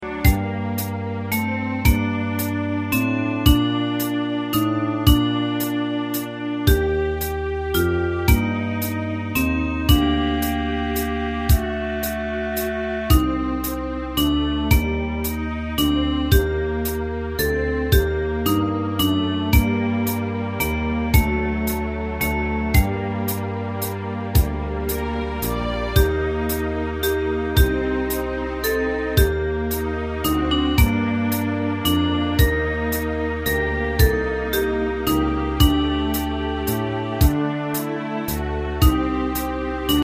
大正琴の「楽譜、練習用の音」データのセットをダウンロードで『すぐに』お届け！